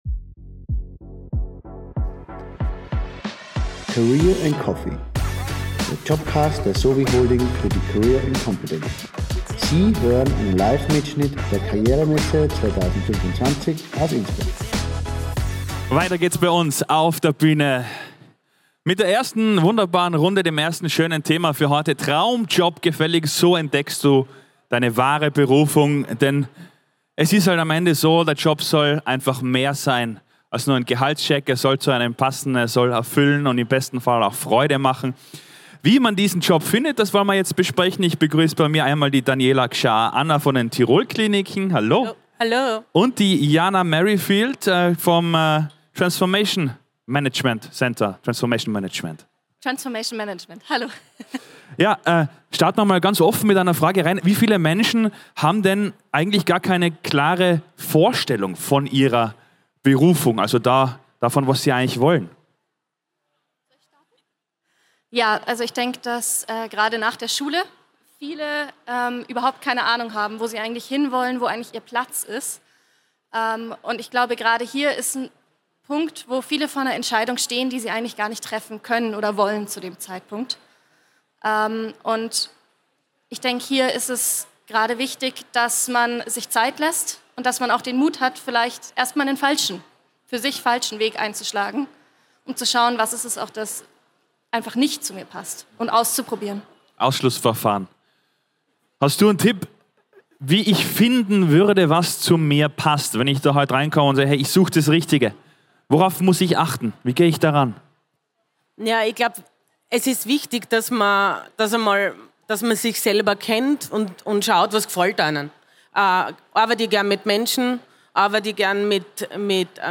Livemitschnitt #2 von der career & competence am 14. Mai 2025 im Congress Innsbruck.